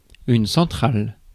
Ääntäminen
Synonyymit centrique Ääntäminen France: IPA: [yn sɑ̃.tʁal] Tuntematon aksentti: IPA: /sɑ̃.tʁal/ Haettu sana löytyi näillä lähdekielillä: ranska Käännös Substantiivit 1. централа Muut/tuntemattomat 2. централен Suku: f .